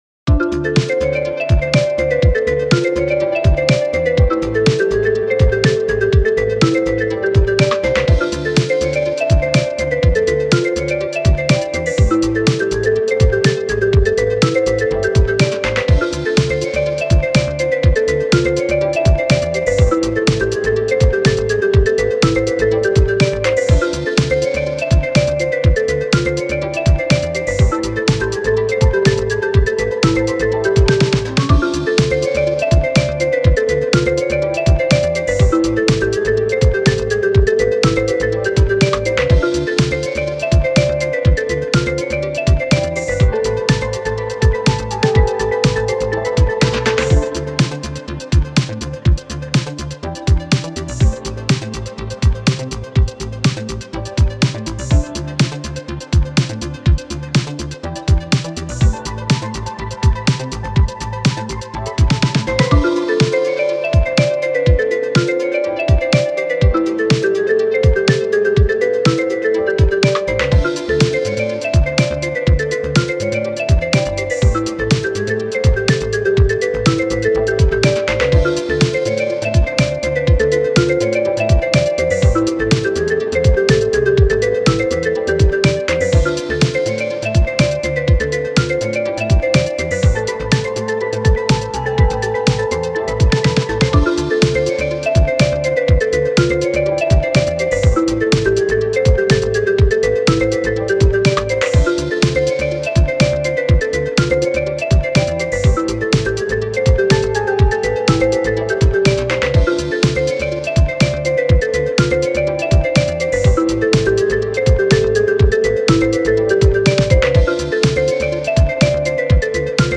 かっこいい エレクトロ オルタナティヴ フリーBGM